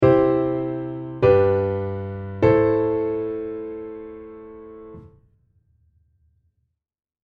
この３つ目の和音には、色んな選択肢があります。